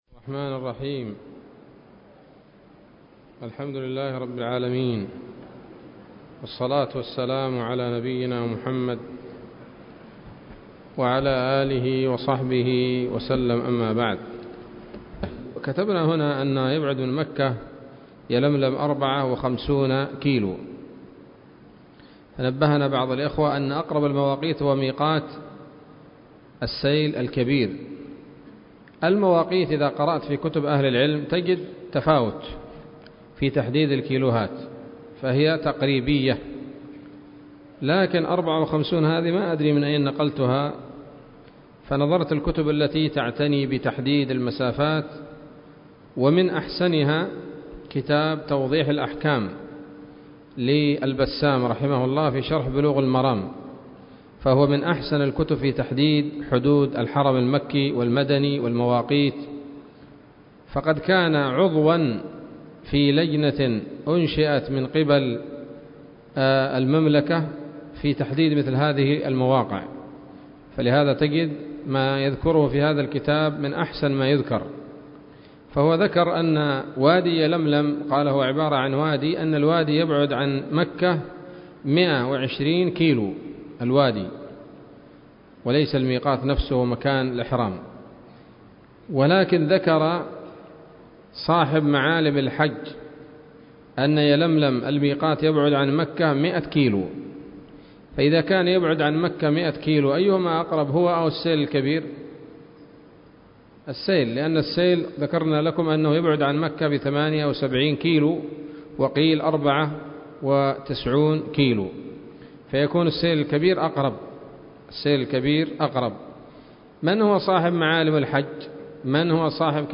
الدرس الثامن من شرح القول الأنيق في حج بيت الله العتيق